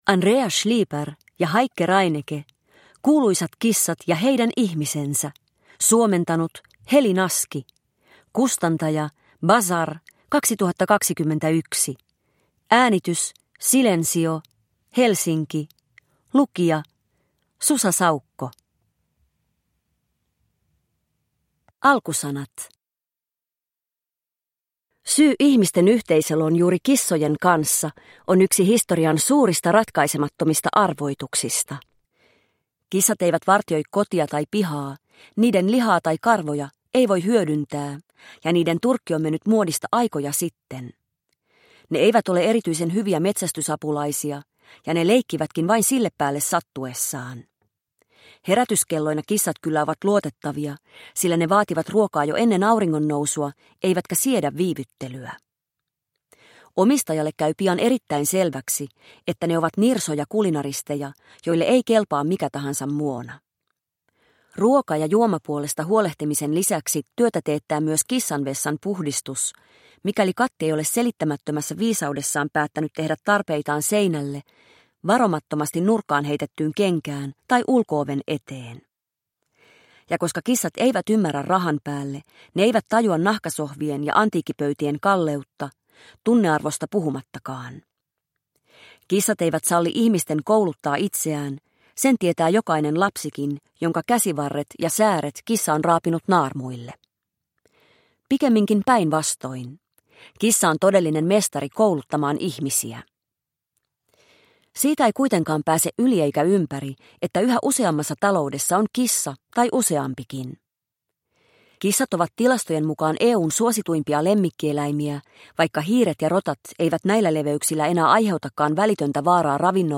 Kuuluisat kissat ja heidän ihmisensä – Ljudbok – Laddas ner